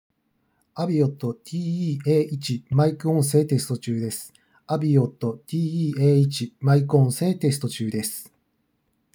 通話音声は上位機種「AVIOT TE-V1R」とほぼ変わらない
✅「AVIOT TE-A1」のマイク音声テスト
うるさい場所だと多少の騒音は入りますが、それでも相手の音声はしっかり聞き取れるレベルです。